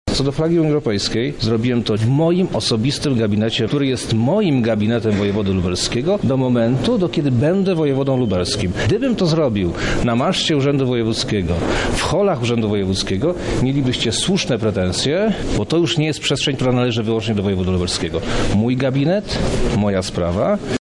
– tłumaczy wojewoda lubelski Przemysław Czarnek.